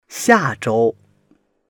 xia4zhou1.mp3